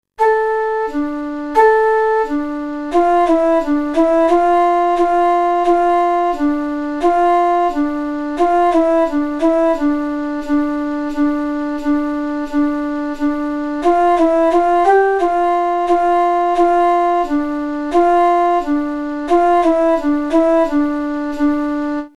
Popular de Hungría, flautas a dos voces y xilófonos.